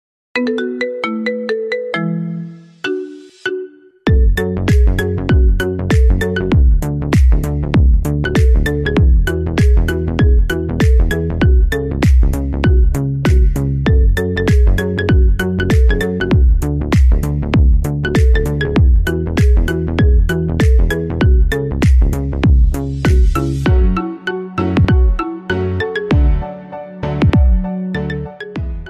SFX苹果手机卡点音效下载
SFX音效